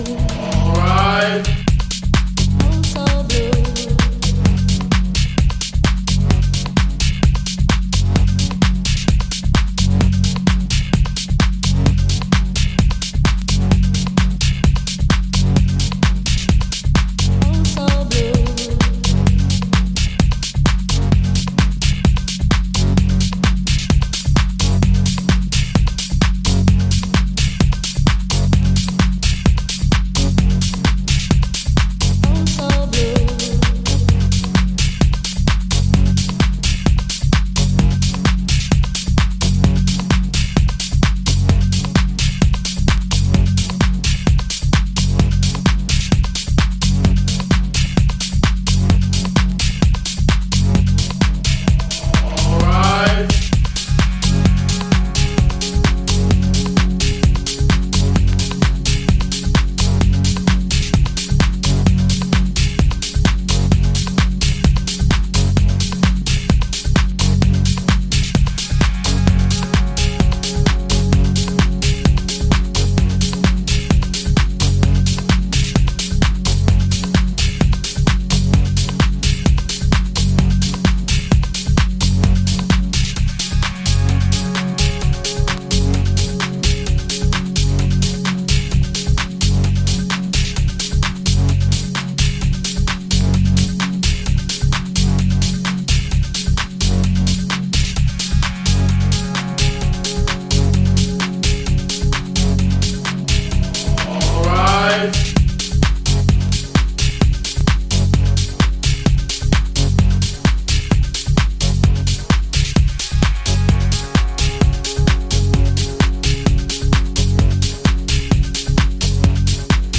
ハットとパーカッションによる追い立てるグルーヴが主役のキラーチューン